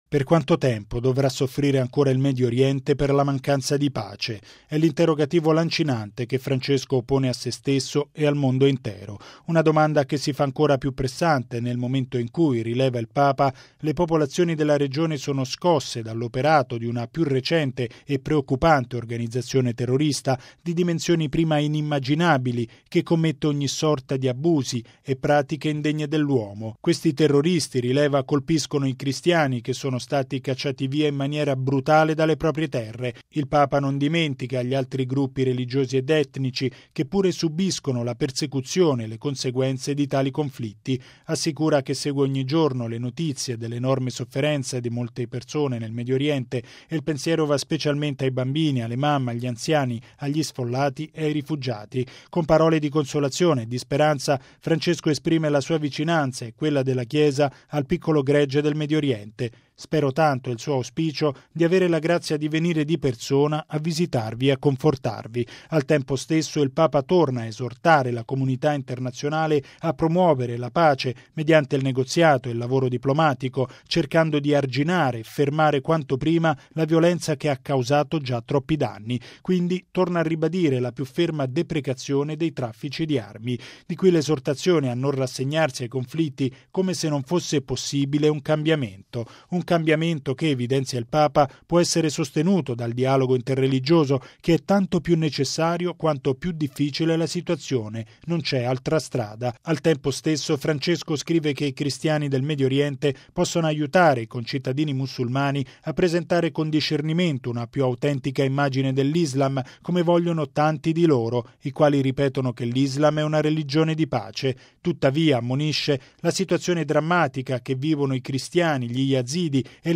Il Papa evidenzia dunque che bisogna proseguire nella via del dialogo con le altre religioni per arrivare alla pace tanto desiderata. Il servizio